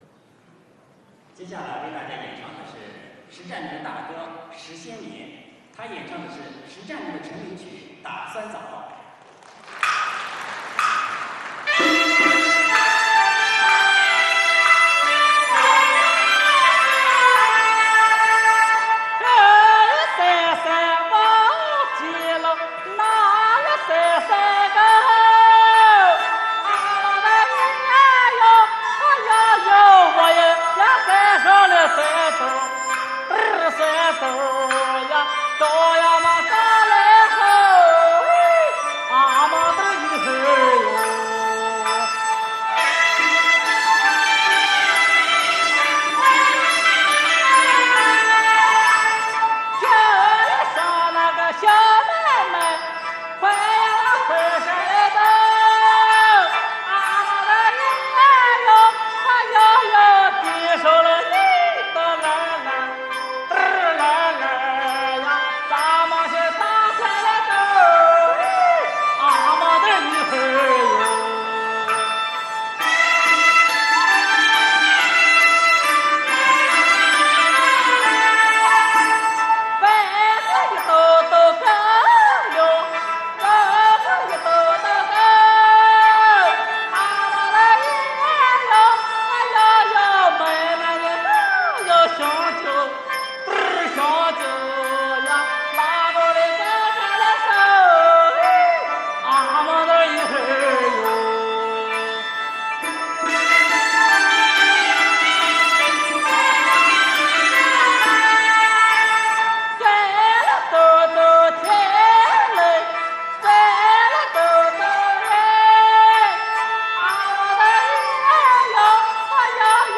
打酸枣 山西晋北左权民歌